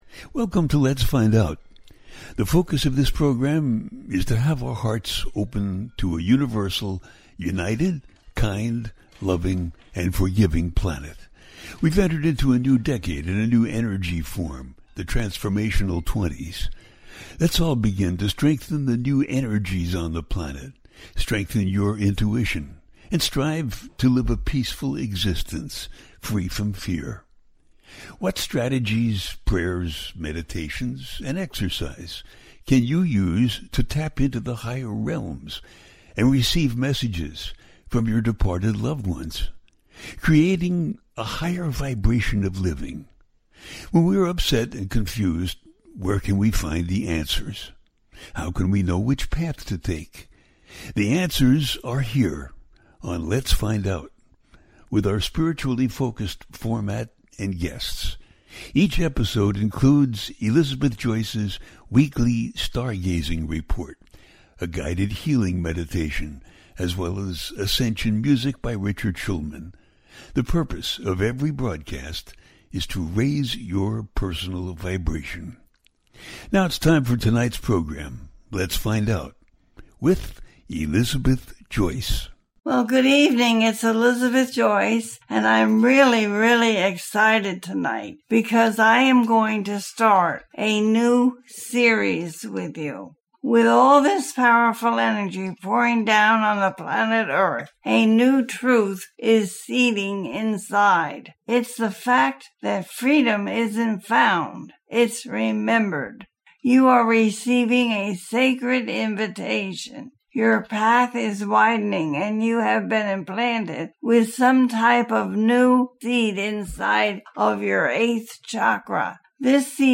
Galaxy Communication and Enhanced Healing - A teaching show